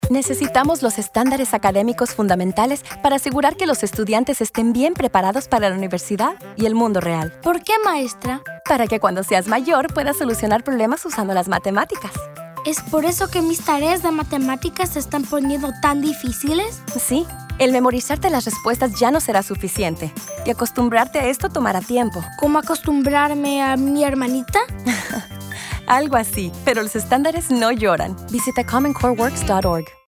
Radio PSAs
Spanish math conversation.mp3